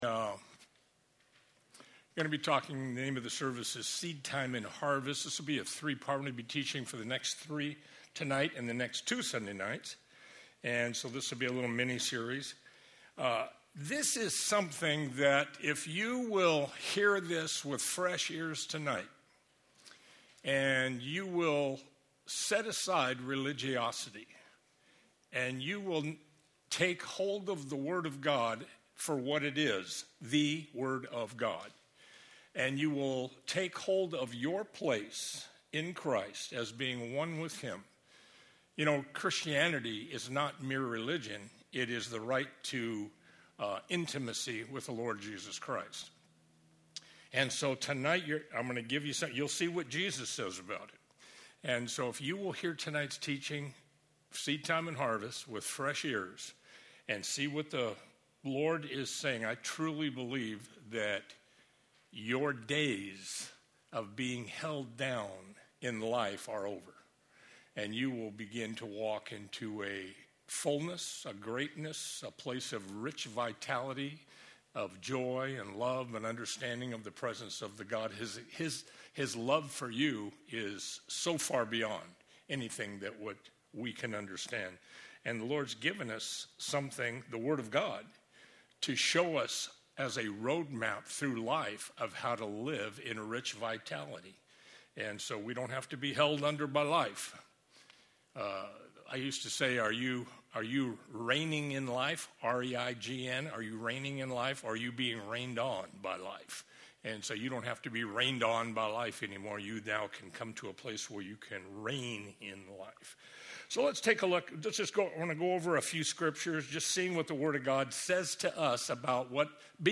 Sunday evening Bible study